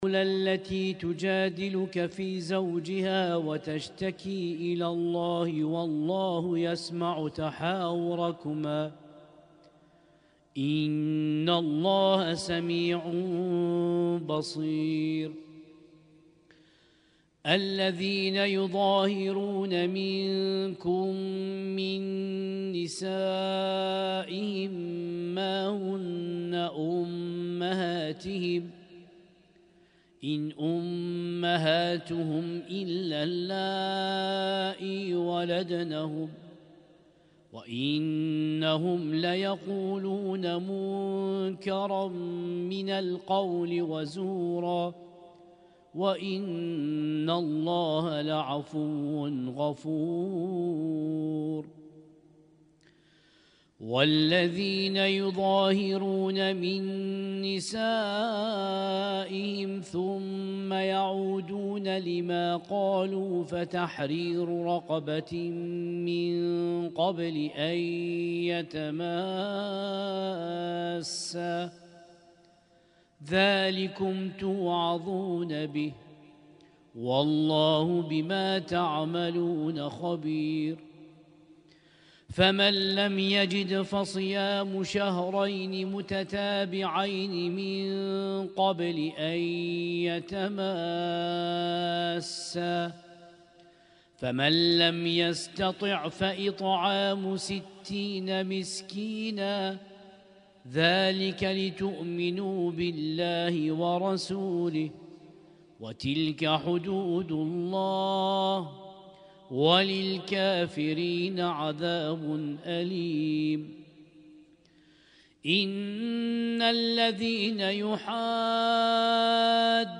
القارئ: القارئ
اسم التصنيف: المـكتبة الصــوتيه >> القرآن الكريم >> القرآن الكريم - شهر رمضان 1446